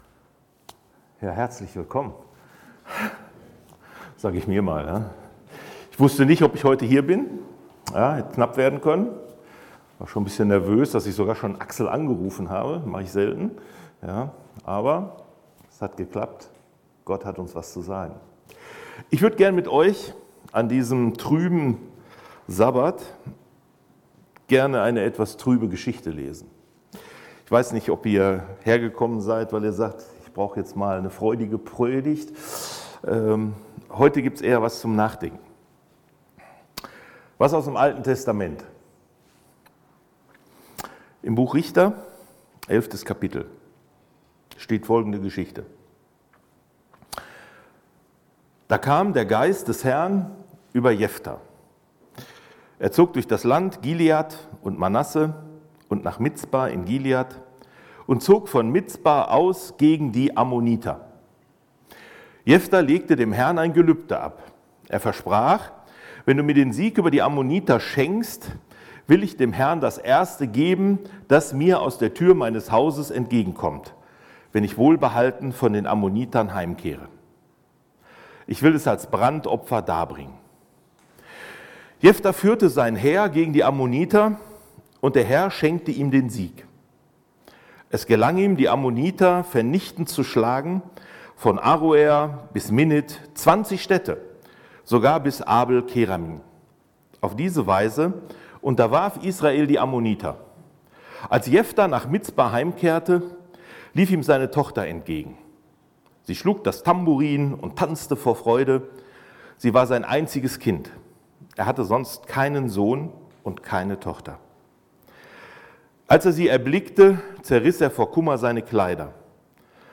2025 in Gottesdienst Keine Kommentare 244 LISTEN